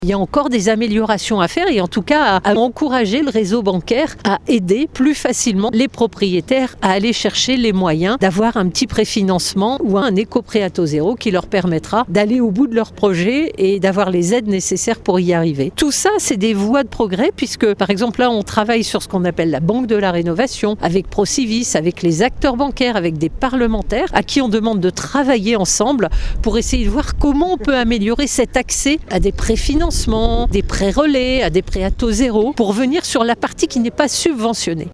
La ministre du logement Valérie Létard :